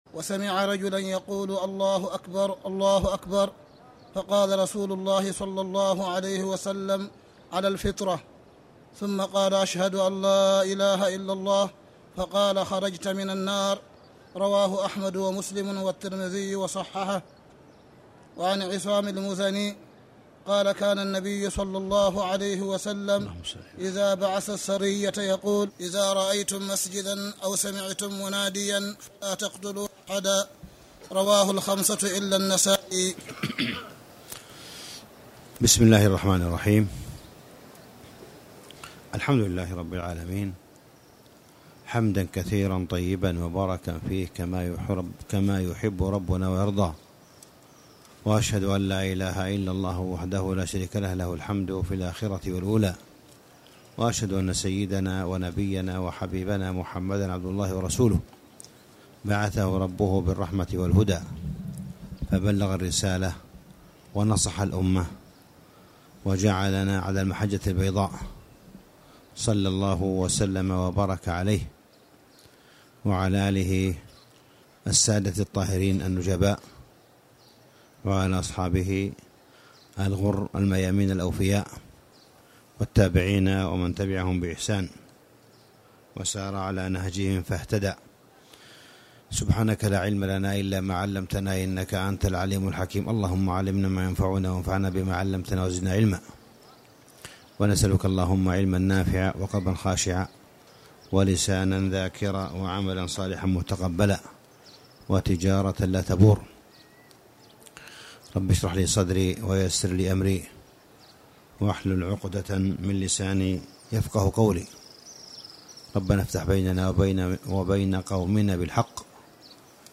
تاريخ النشر ٦ رمضان ١٤٣٧ هـ المكان: المسجد الحرام الشيخ: معالي الشيخ أ.د. صالح بن عبدالله بن حميد معالي الشيخ أ.د. صالح بن عبدالله بن حميد نيل الاوطار كتاب الجهاد (4)